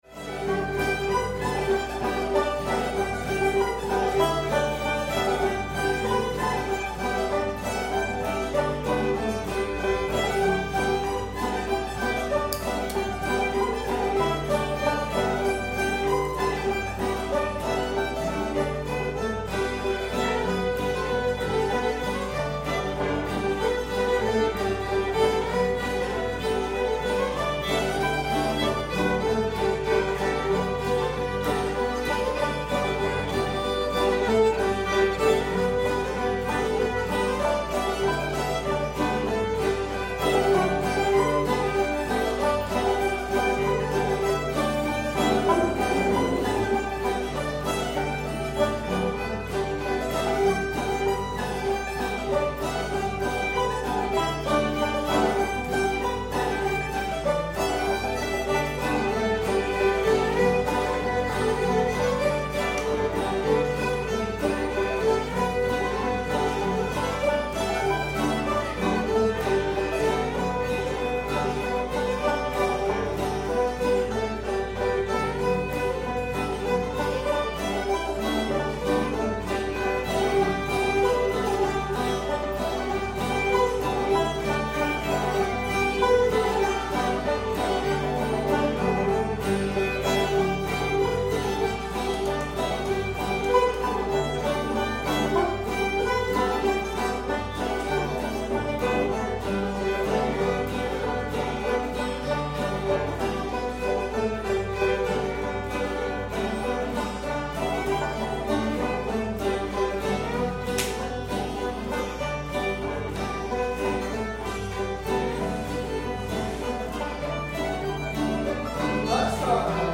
hezekiah [G]